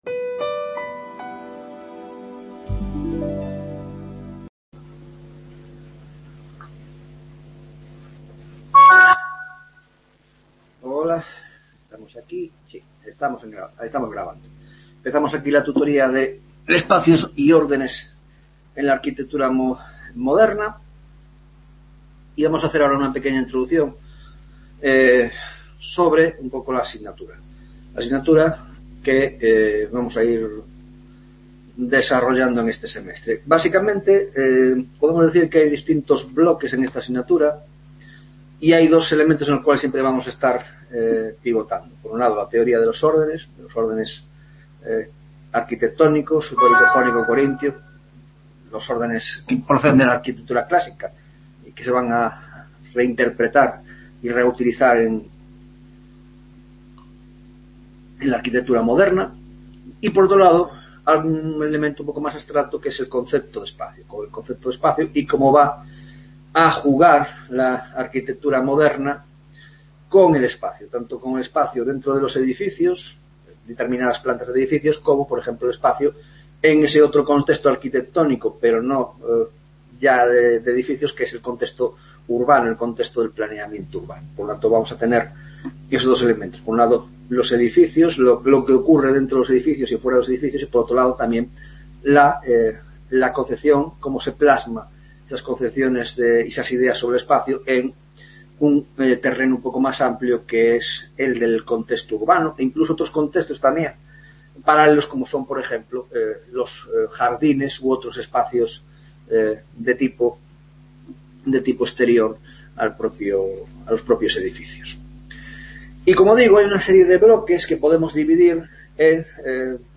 1ª Tutoría de Órdenes y Espacio en la Arquitectura Moderna - Introducción: La Concepción del Espacio (1ª parte)